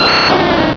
Cri d'Herbizarre dans Pokémon Rubis et Saphir.
Cri_0002_RS.ogg